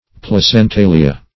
Placentalia \Plac`en*ta"li*a\, n. pl. [NL.] (Zool.)